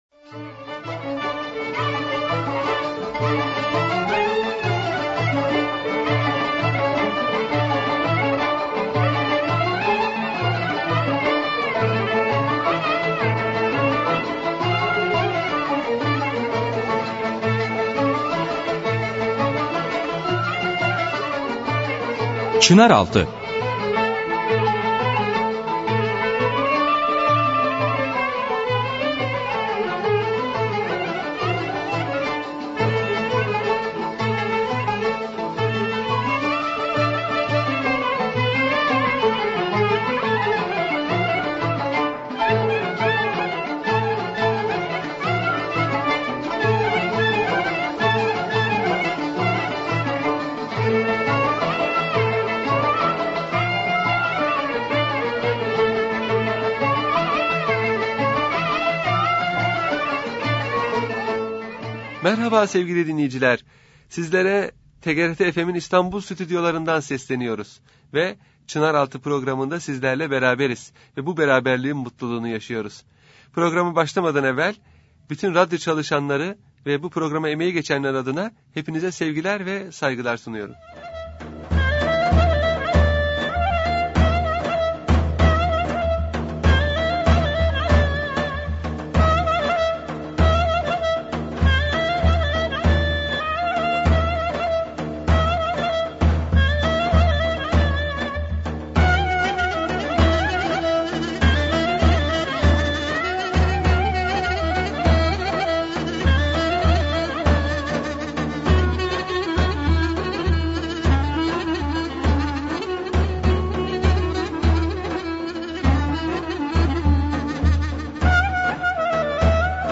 Radyo Programi - Antep